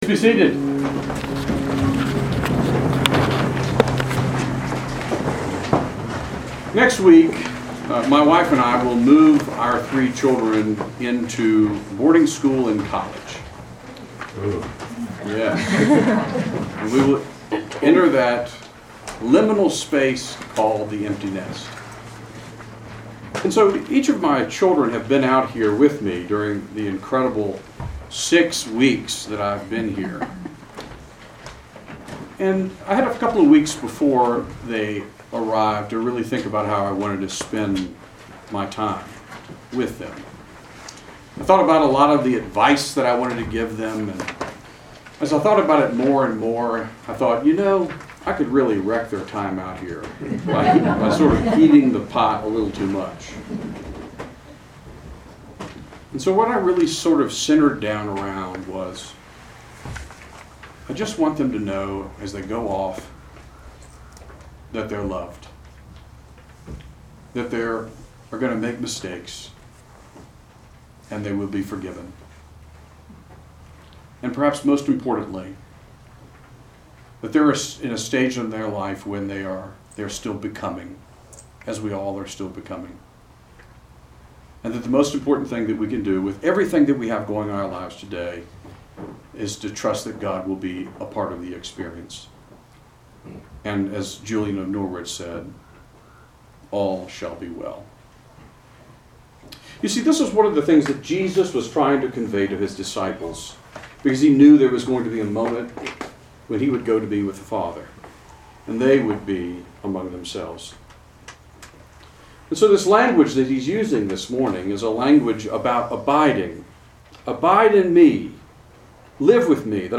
Sermons
St. John's Episcopal Church Chapel of the Transfiguration